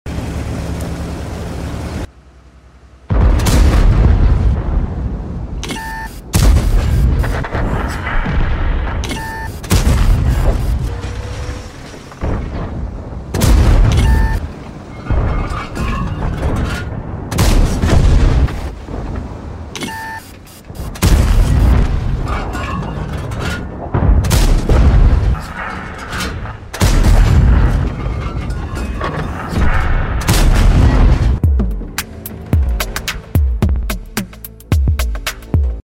World of Tanks WZ-120-1GFT part 1 restless gameplay